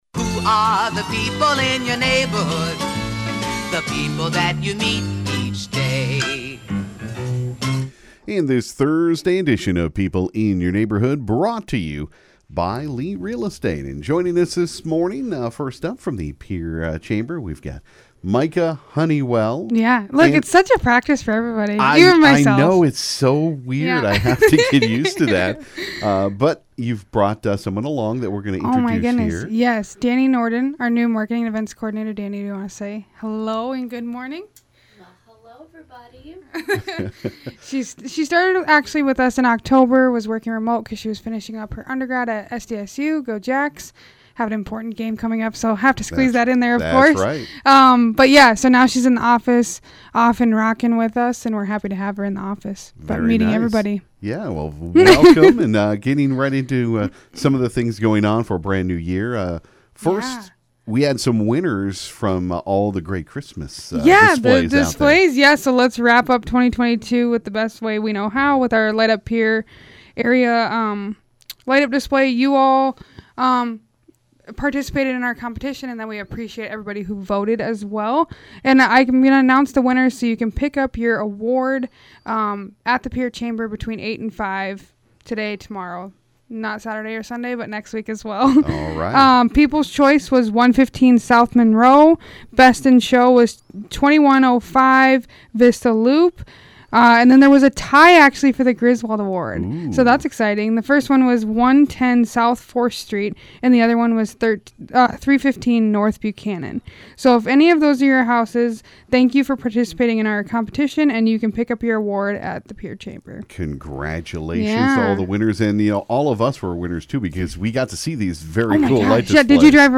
Ft. Pierre Mayor Gloria Hanson stopped by for her weekly visit.